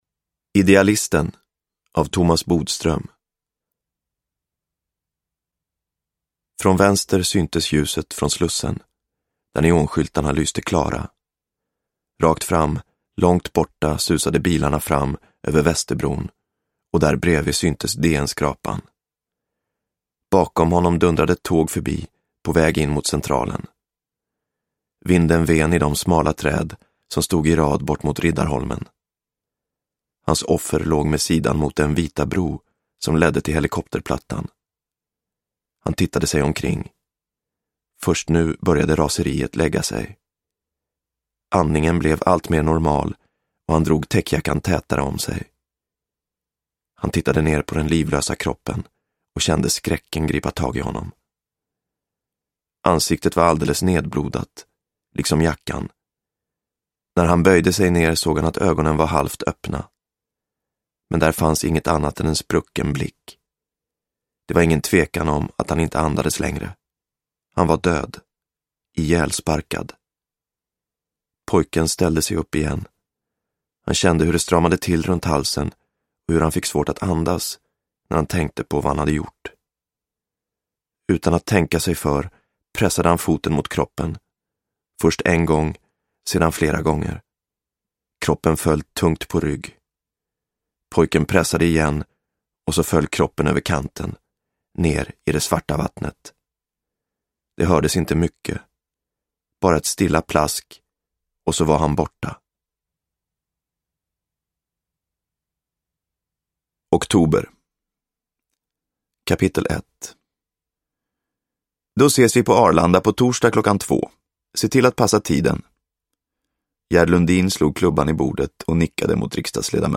Idealisten – Ljudbok – Laddas ner
Uppläsare: Ola Rapace